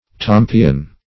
Tompion \Tom"pi*on\, n. [See Tampios]